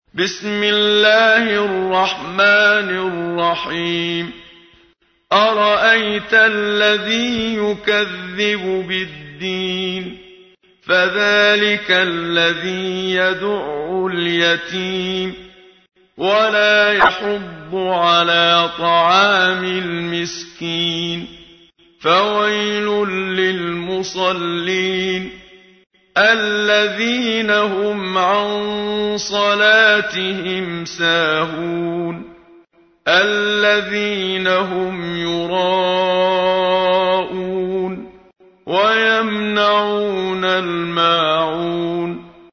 تحميل : 107. سورة الماعون / القارئ محمد صديق المنشاوي / القرآن الكريم / موقع يا حسين